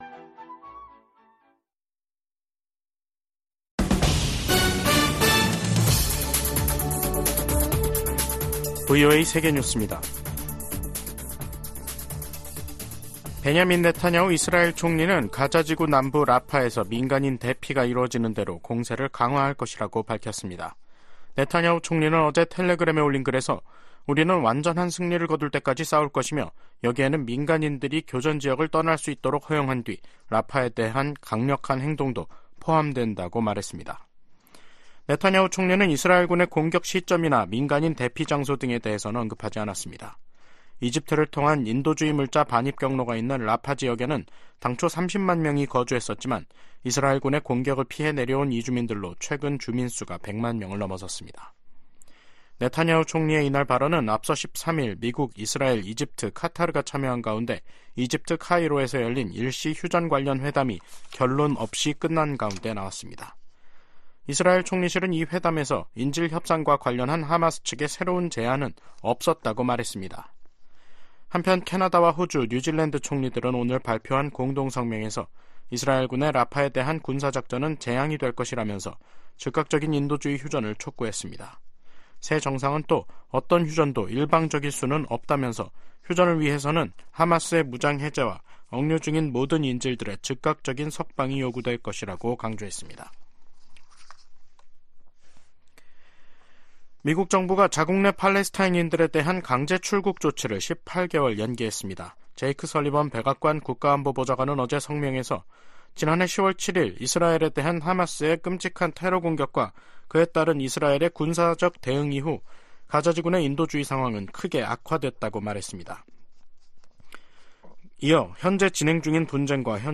세계 뉴스와 함께 미국의 모든 것을 소개하는 '생방송 여기는 워싱턴입니다', 2024년 2월 15일 저녁 방송입니다. '지구촌 오늘'에서는 한국-쿠바가 65년 만에 외교관계를 복원한 소식 전해드리고, '아메리카 나우'에서는 슈퍼볼 승리 퍼레이드 현장에서 총격으로 사상자 22명이 발생한 이야기 살펴보겠습니다.